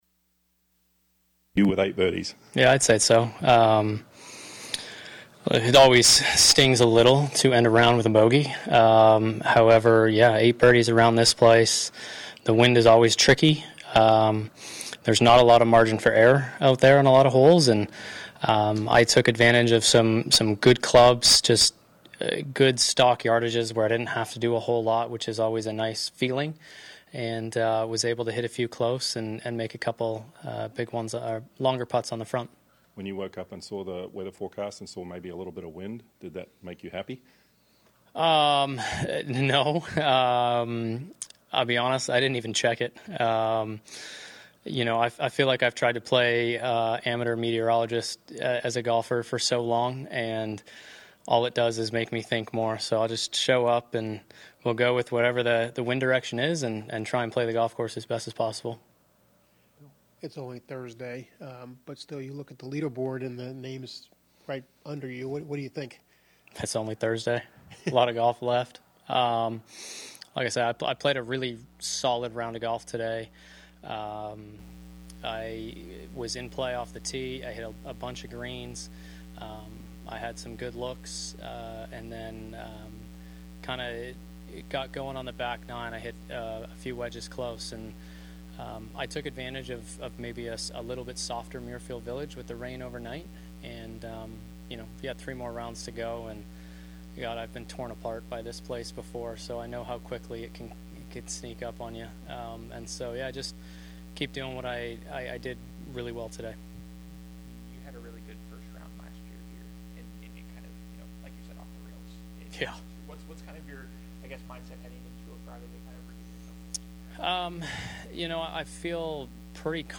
Memorial Tournament News Conference: Adam Hadwin leads after First Round by a stroke over Scottie Scheffler
Adam Hadwin Memorial Tournament 1st Round Leader News Conference June 6, 2024.mp3